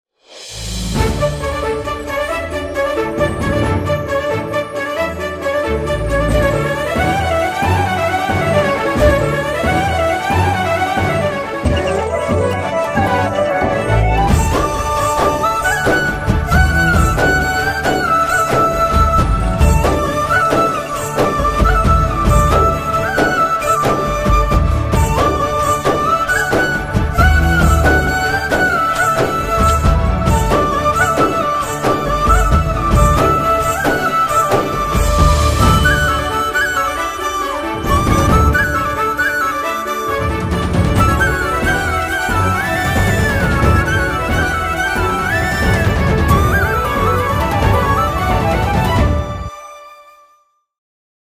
We made it like an official one with proper sound balance.